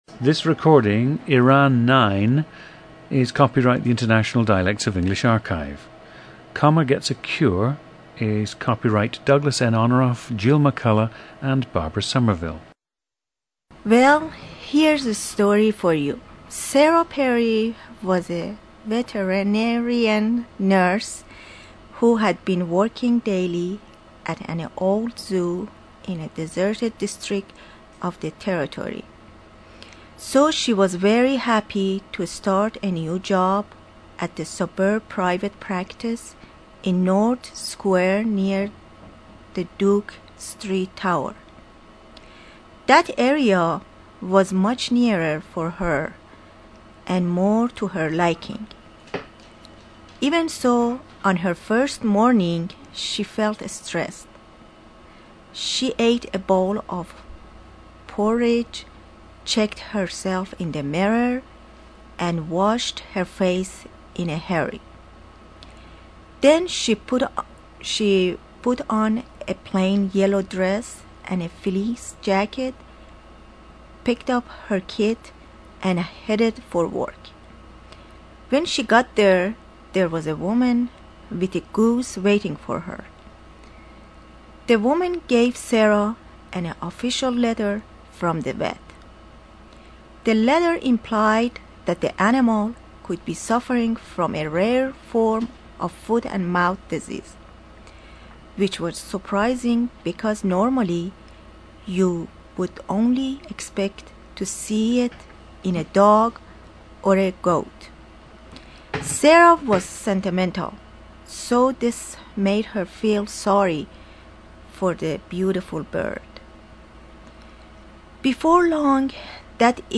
Listen to Iran 9, a 49-year-old woman from Tehran, Iran, who has also lived in the United States.
GENDER: female
ETHNICITY: Iranian (exact ethnicity unknown)
The subject moved to the United States in the 1970s.
The recordings average four minutes in length and feature both the reading of one of two standard passages, and some unscripted speech.